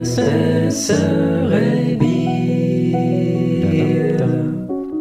Ganz am Ende werden die MIDI-Spuren für den Gesang auf stumm geschaltet und schon haben wir ein ganz ordentlich klingendes Quartett mit Klavierbegleitung:
Ich konnte es mir einfach nicht verkneifen, mir selbst zwei kleine Cameo-Auftritte zu gönnen und habe deshalb für einen davon ein paar zusätzliche Noten in den “Final Mix” geschmuggelt.
Final Mix mit geheimer Zutat